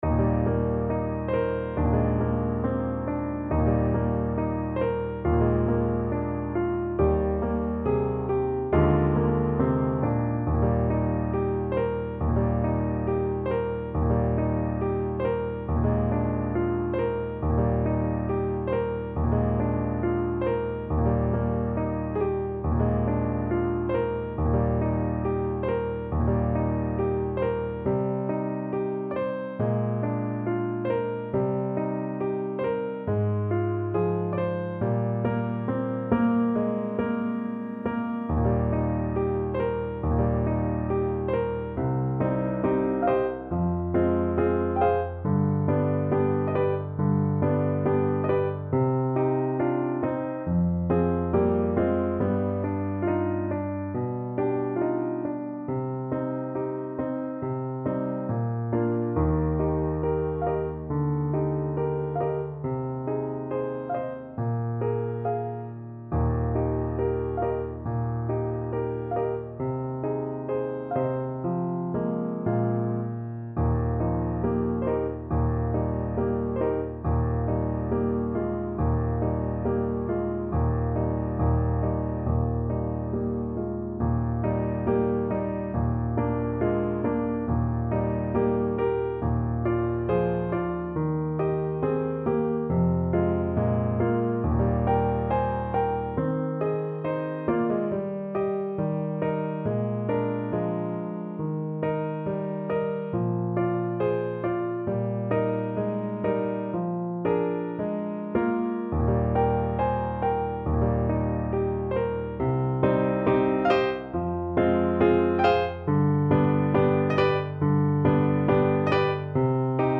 ~ = 69 Andante tranquillo